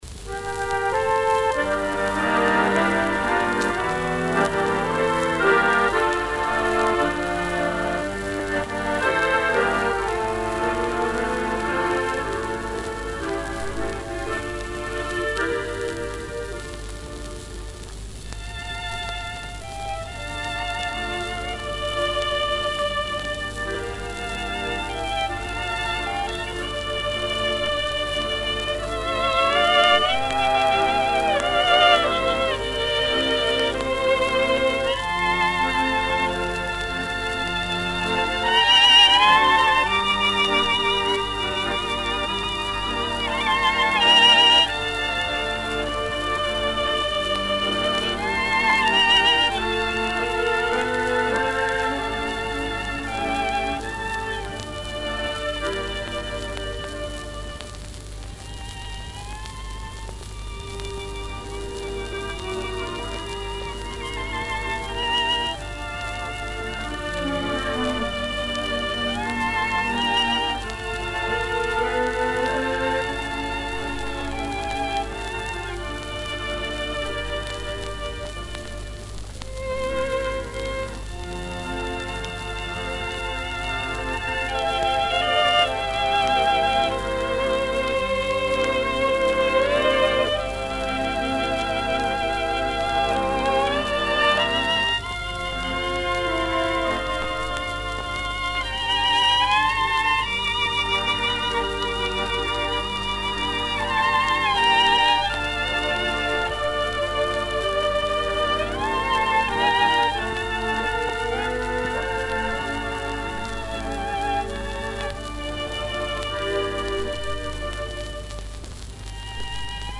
Описание: Блокадная запись.